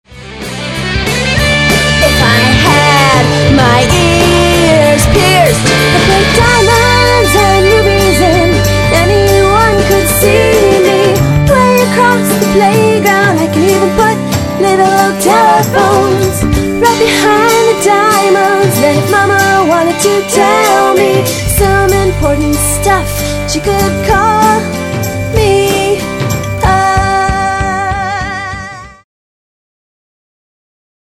VOCAL DEMO
animation demo track